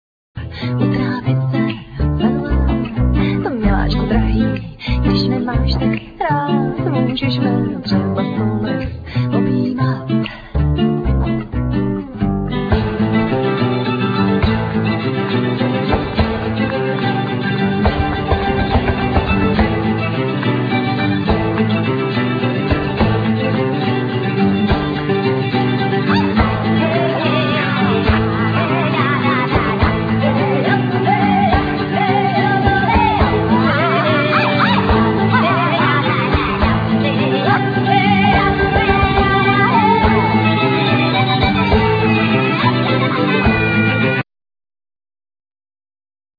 Violin,Kalimba,Percussion,Viola,Vocal,Africanlyra
Guitar,Sas,Vocal,Percussion,Mandolin
Cello
Trumpet
Double bass